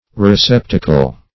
Receptacle \Re*cep"ta*cle\ (r[-e]*s[e^]p"t[.a]*k'l), n. [F.